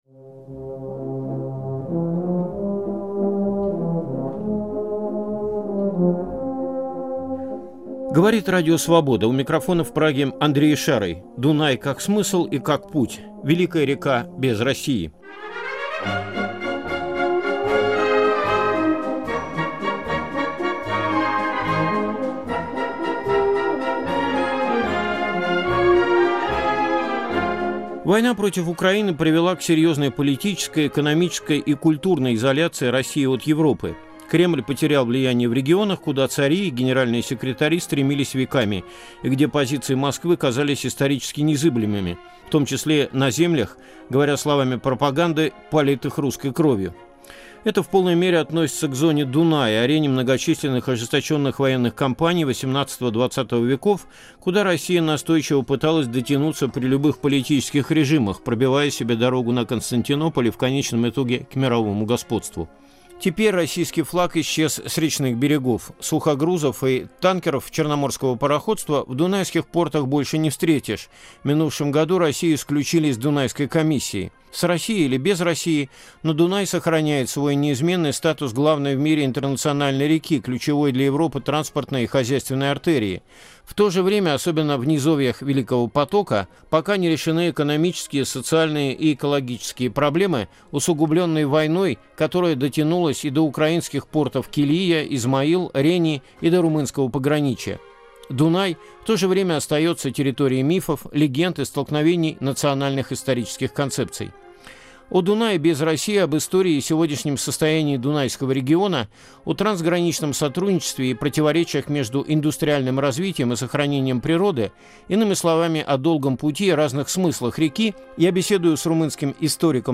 Великая река без России. Беседа